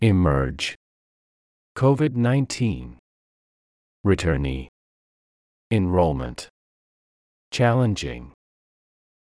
emerge /ɪˈmɜːrdʒ/（動）現れる、明らかになる
COVID-19 /ˌkoʊˌvɪd ˌnaɪnˈtiːn/（名）新型コロナウイルス
returnee /ˌriːtɜːrˈniː/（名）帰国者、復帰者
enrollment /ɪnˈroʊlmənt/（名）登録、入学、入会
challenging /ˈtʃælɪndʒɪŋ/（形）困難な、やりがいのある